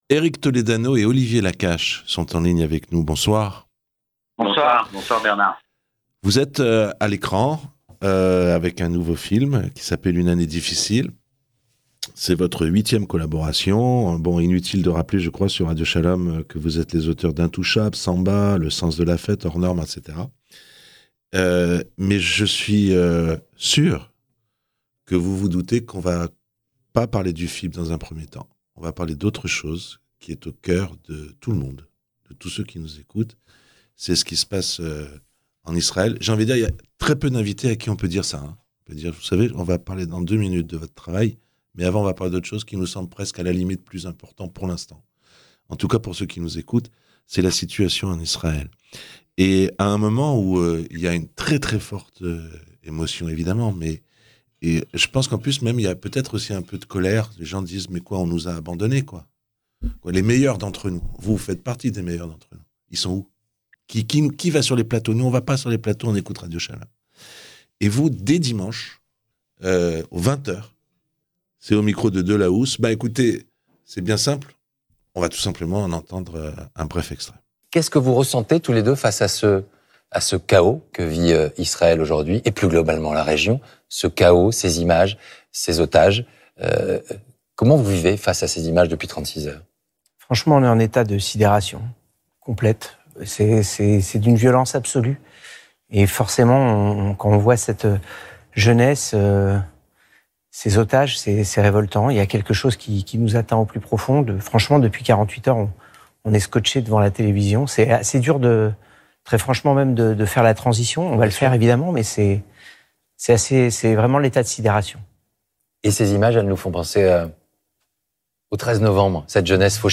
Alors que la polémique enfle sur le silence de nombreux artistes qui se taisent depuis le massacre de samedi dernier, en Israël, les deux réalisateurs, Olivier Nakache et Eric Toledano, ont exprimé, sur Radio Shalom, leur profonde révolte devant ces crimes. Ils évoquent le Bataclan mais aussi la Seconde Guerre mondiale.